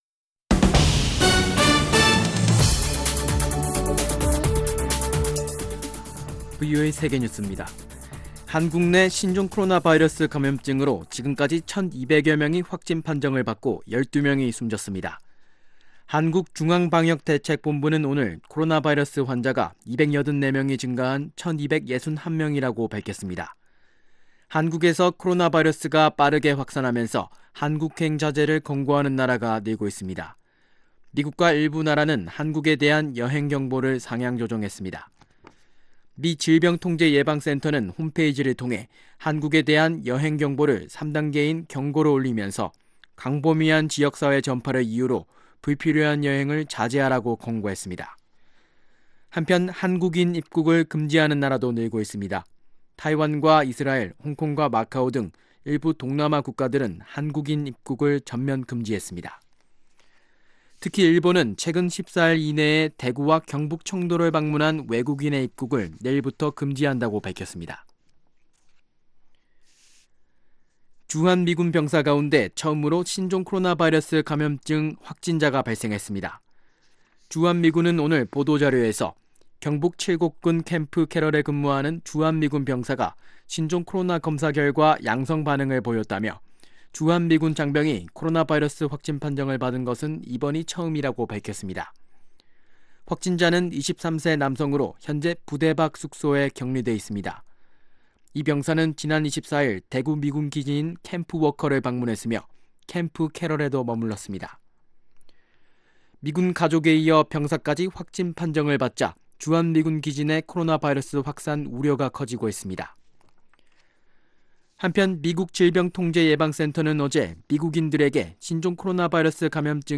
VOA 한국어 간판 뉴스 프로그램 '뉴스 투데이', 2019년 2월 26일 2부 방송입니다. 한국의 신종 코로나바이러스 확진자가 1200명을 넘어섰고, 주한미군 병사 가운데 첫 확진자가 나왔습니다. 영국과 일본 등 군축회의 참가국들이 북한의 핵과 대량살상무기 확산이 안보에 심각한 위협이라고 지적했습니다.